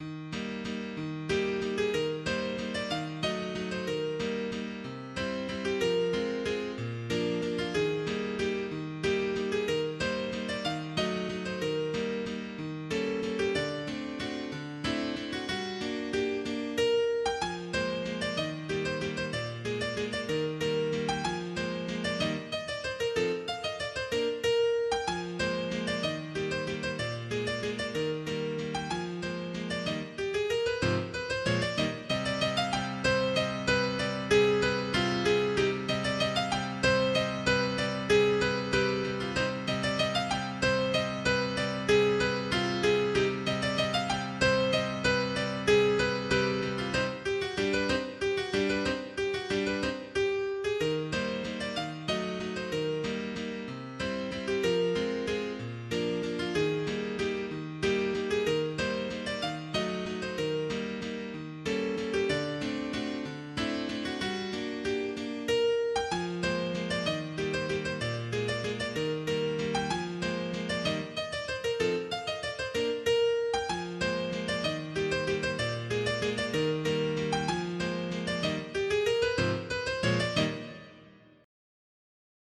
Obra en compás ternario del compositor ruso P. Chaikovsky
Es una sencilla pieza para piano donde es posible reconocer su compás ternario y su estructura ABA (tema, desarrollo del tema y reexposición del mismo) con uno diseño melódico fluido y cantable.
vals
ritmo ternario